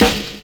N.Y RAP    3.wav